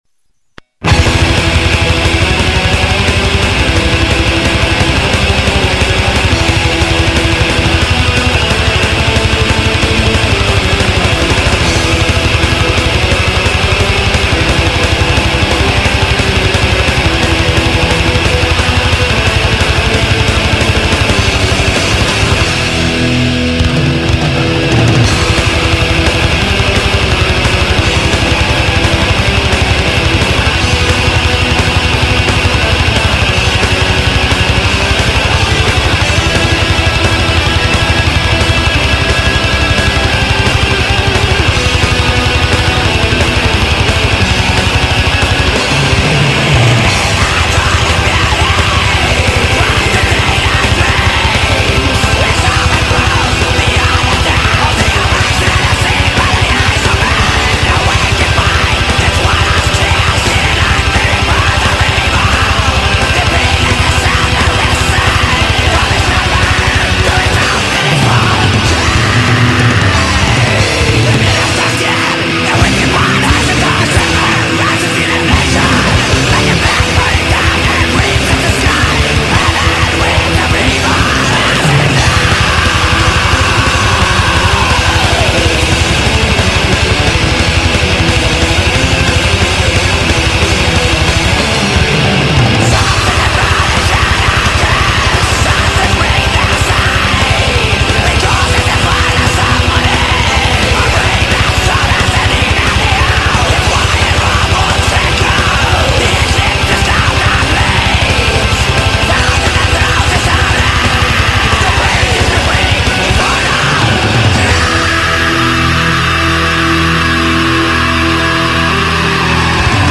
Blues